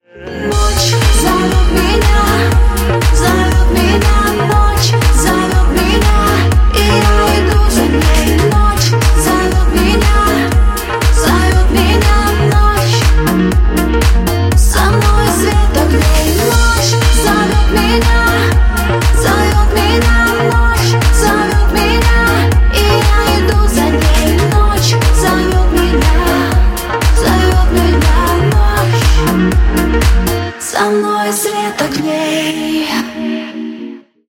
• Качество: 146, Stereo
dance
vocal